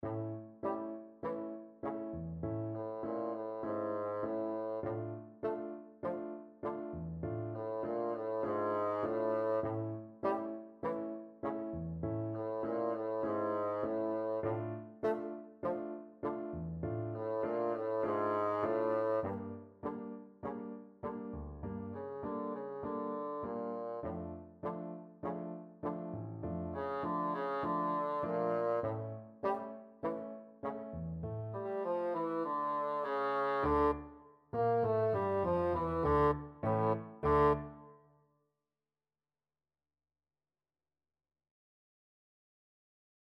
4/4 (View more 4/4 Music)
Tempo di Tango
World (View more World Bassoon Music)